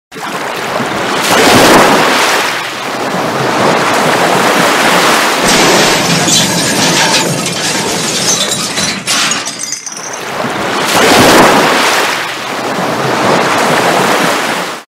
break.mp3